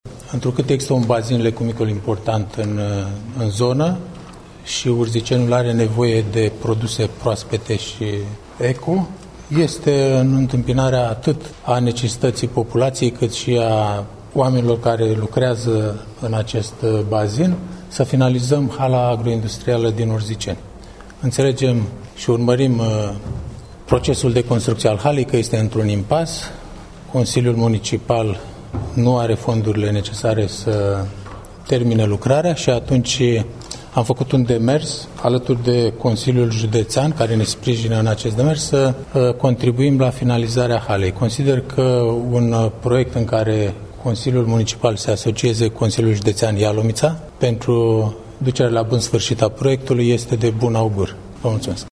Consiliul Local al Municipiului Urziceni s-a reunit aseară în şedinţă extraordinară, pentru aprobarea asocierii municipalităţii cu Consiliul Judeţean Ialomiţa în vederea finanţării în comun şi finalizării investiţiei la ,,Hala Agroalimentară din piaţa Municipiului Urziceni’’.
Iniţiatorii proiectului de hotărâre au expus prin vocea consilierului Liviu Cazan motivele demersului pe care l-au supus aprobării legislativului: